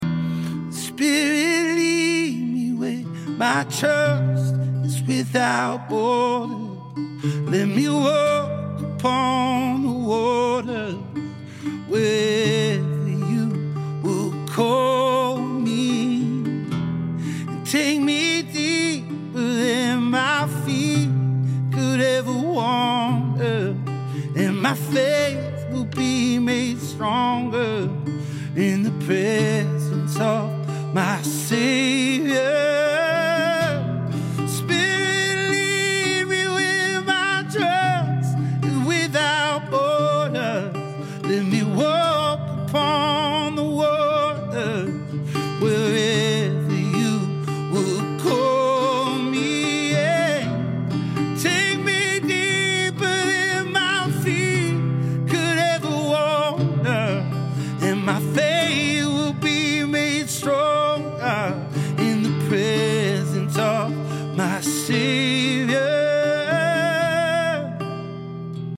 Acoustic Worship